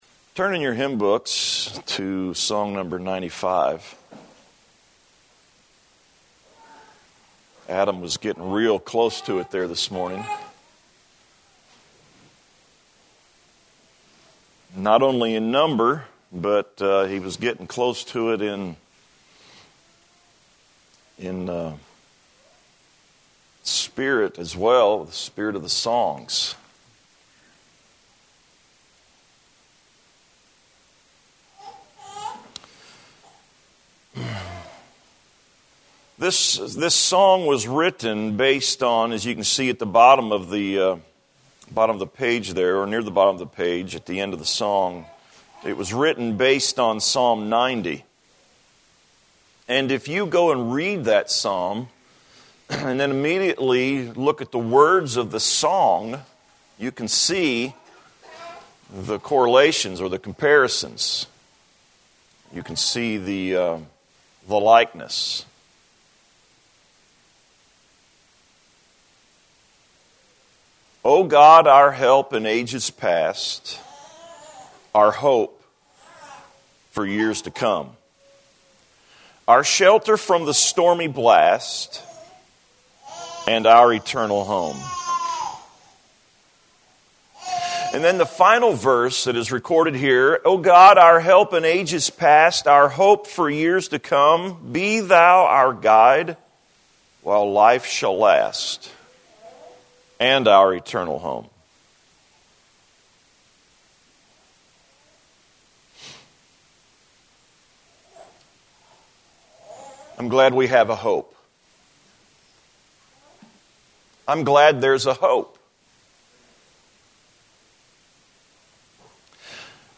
2018-10-28-am-sermon.mp3